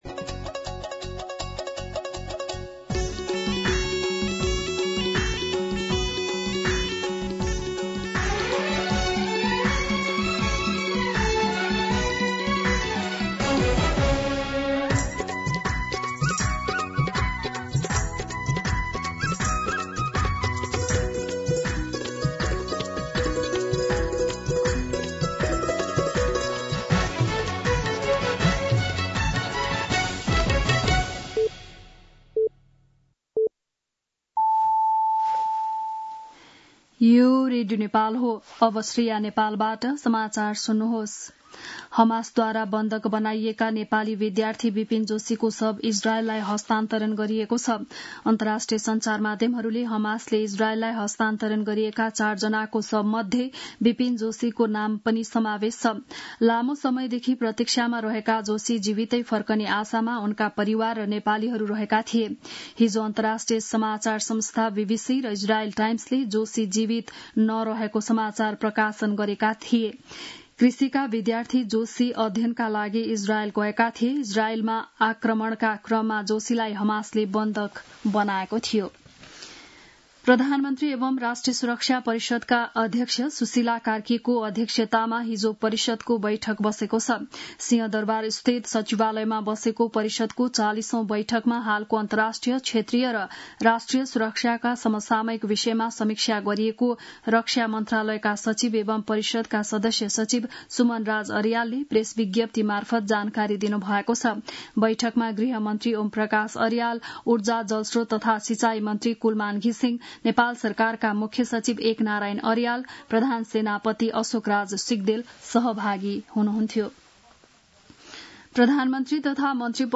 बिहान ११ बजेको नेपाली समाचार : २८ असोज , २०८२